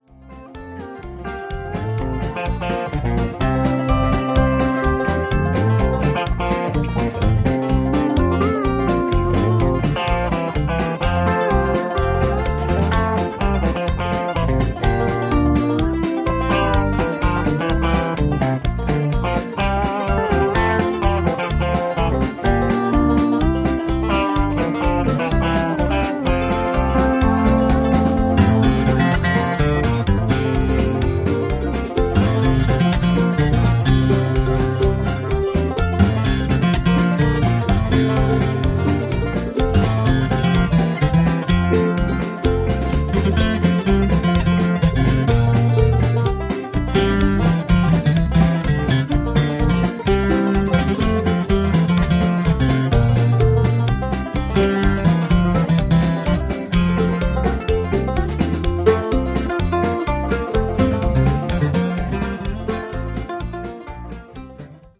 (Low Key)